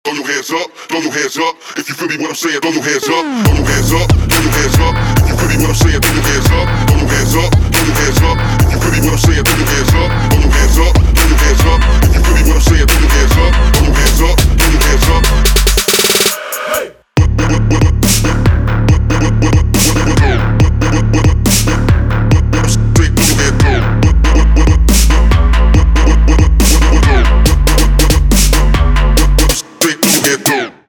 • Качество: 320, Stereo
Bass